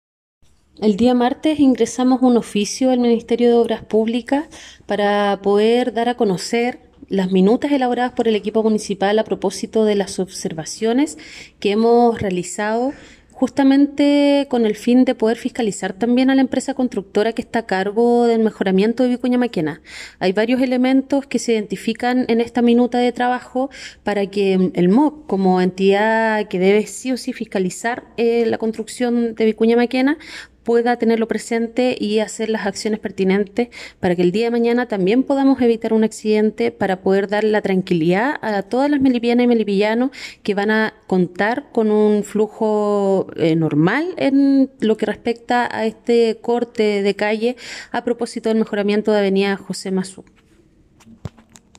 Al respecto, la Alcaldesa Lorena Olavarría, manifestó que el oficio ingresado al Ministerio de Obras Públicas, busca fiscalizar a la empresa constructora a cargo.